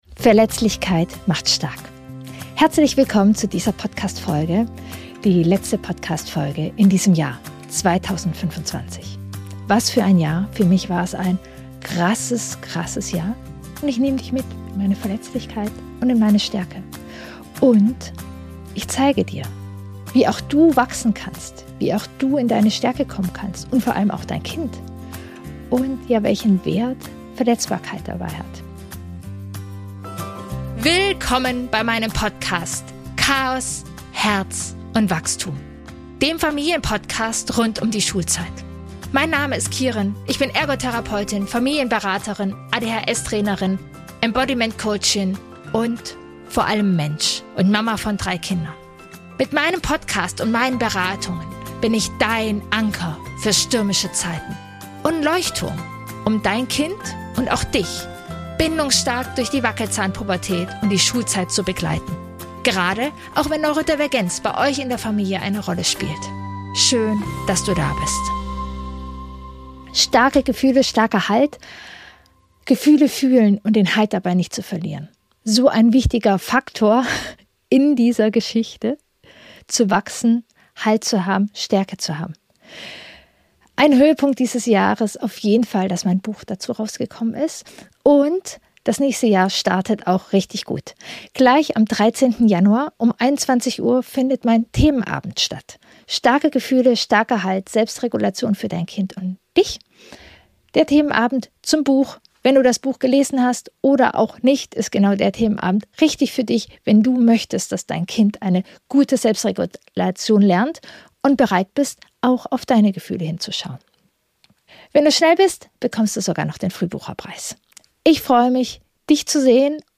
Sie ist leiser.